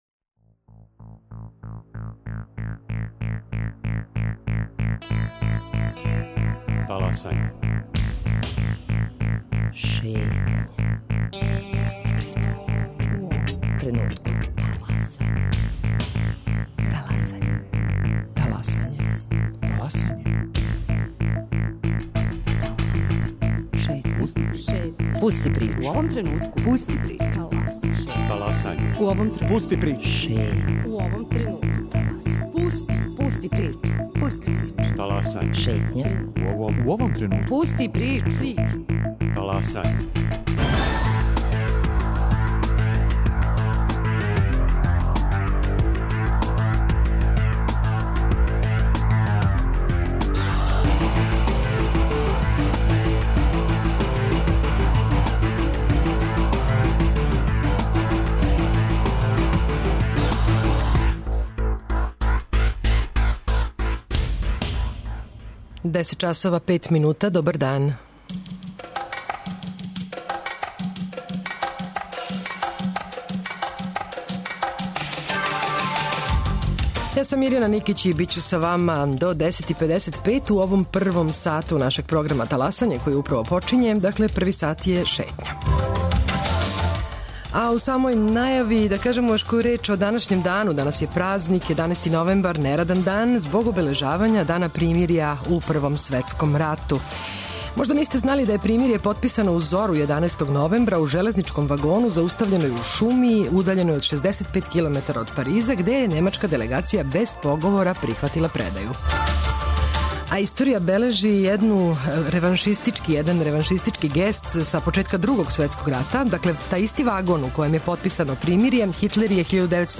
Теме око којих могу изградити своје истраживање су разноврсне - од поља друштвених, техничких наука, економије, јапанског језика и књижевности до културе. Гост Шетње је прошлогодишњи добитник ове стипендије.